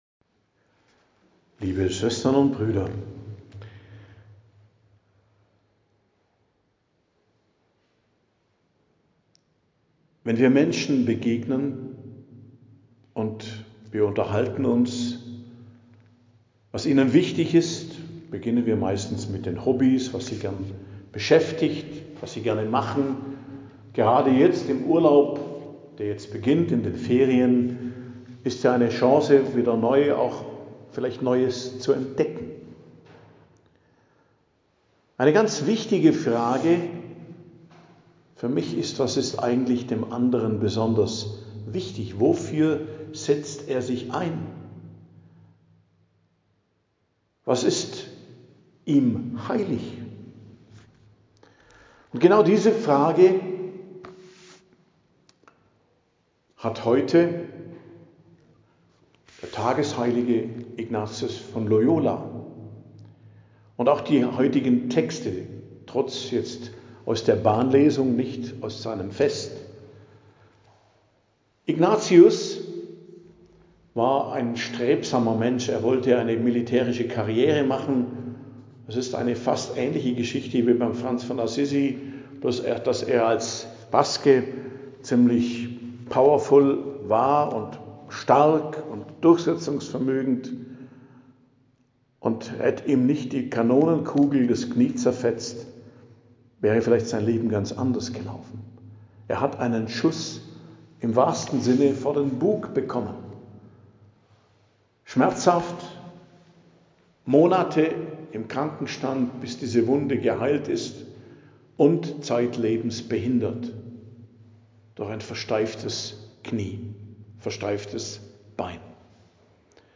Predigt am Donnerstag der 17. Woche i.J., 31.07.2025 ~ Geistliches Zentrum Kloster Heiligkreuztal Podcast